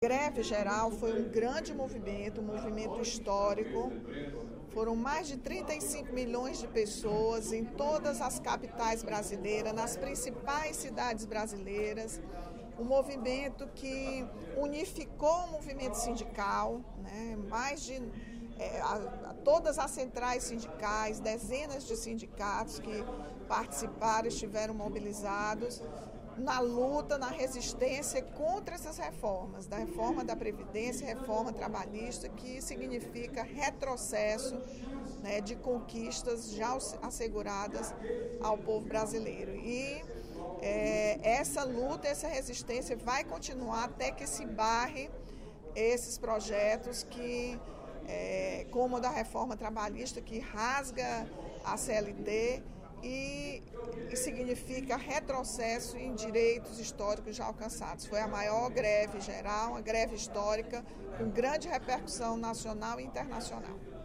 A deputada Rachel Marques (PT) comemorou, nesta quarta-feira (03/05), durante o primeiro expediente da sessão plenária da Assembleia Legislativa, a “grande adesão” registrada na greve geral da última sexta-feira (28/03).